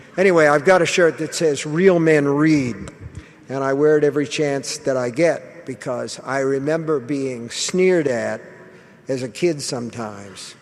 sneered-at-strong-vowel-Stephen-King.mp3